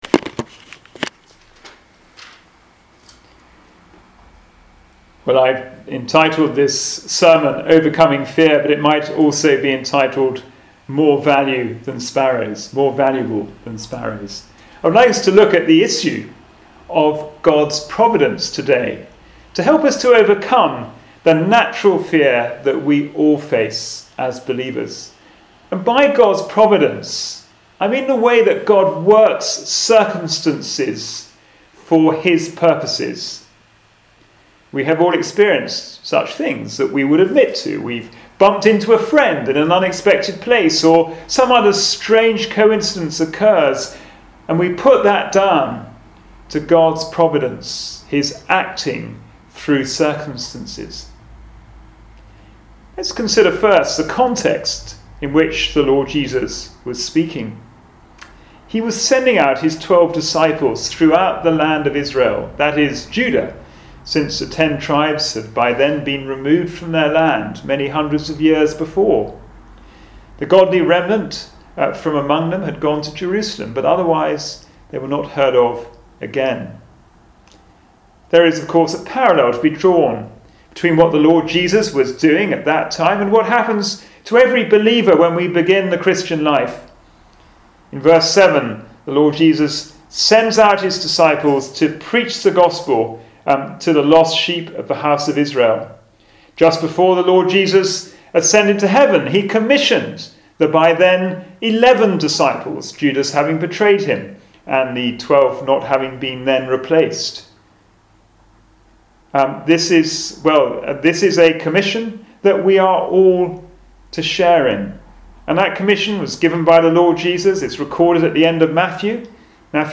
Passage: Matthew 10:26-33 Service Type: Sunday Morning Service